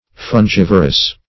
Search Result for " fungivorous" : The Collaborative International Dictionary of English v.0.48: Fungivorous \Fun*giv"o*rous\, a. [L. fungus + vorare to eat greedily: cf. F. fongivore.]